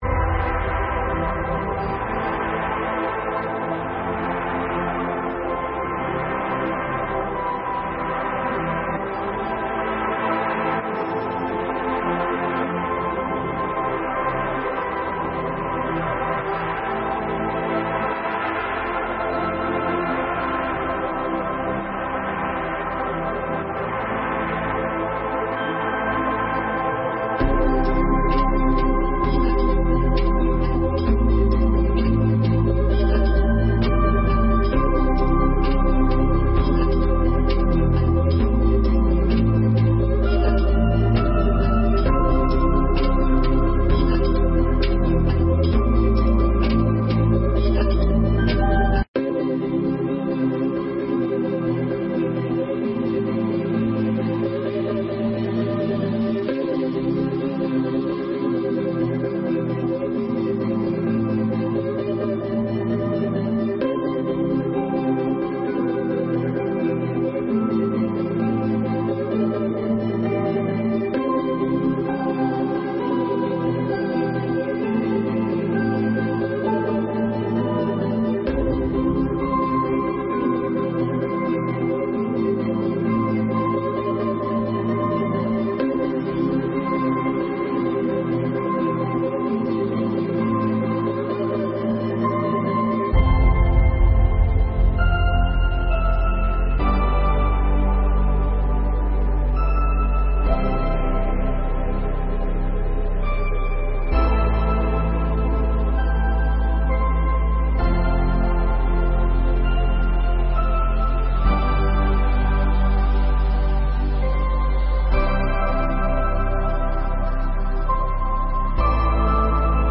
4ª Sessão Extraordinária de 2021